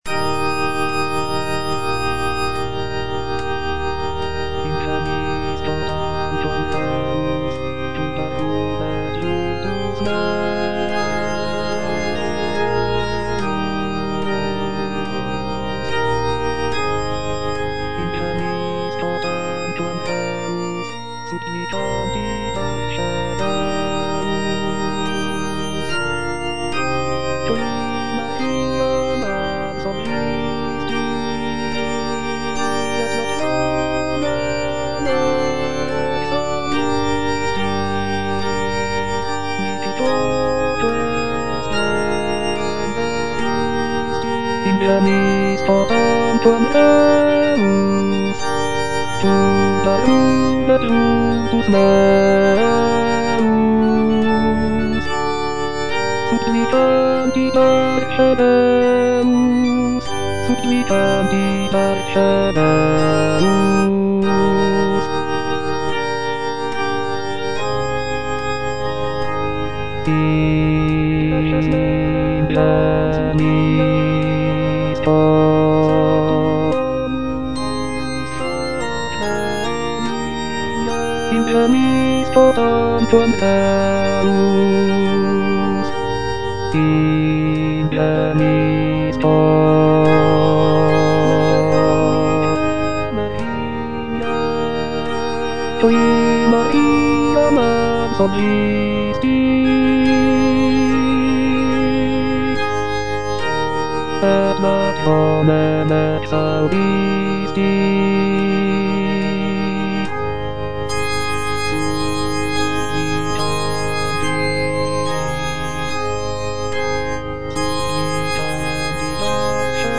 (bass I) (Voice with metronome) Ads stop
is a sacred choral work rooted in his Christian faith.